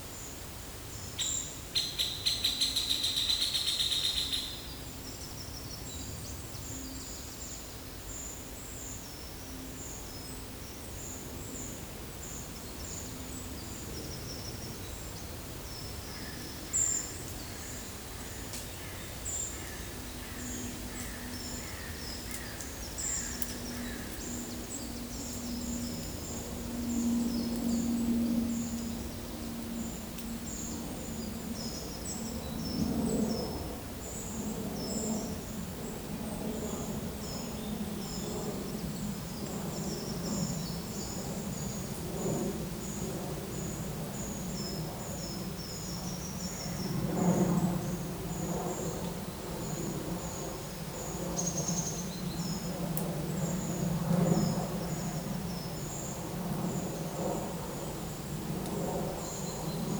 Monitor PAM
Turdus iliacus
Certhia familiaris
Certhia brachydactyla
Leiopicus medius